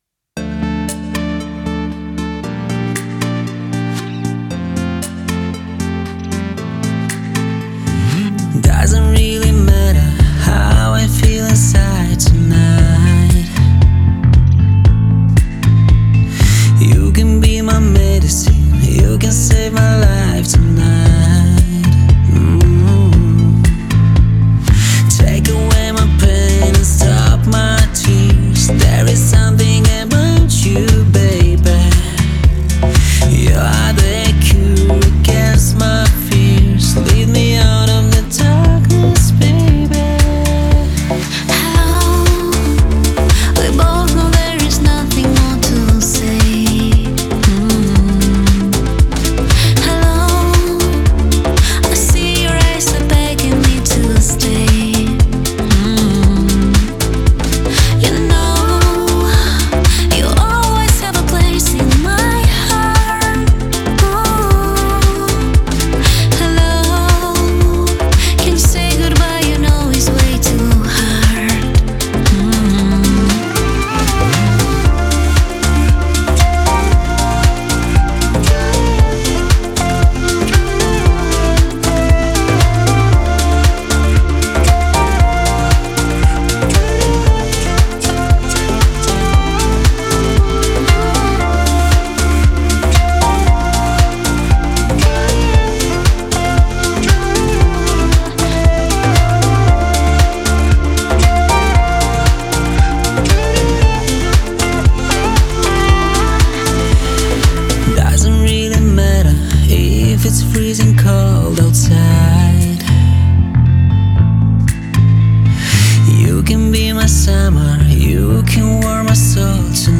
это яркая и запоминающаяся поп-композиция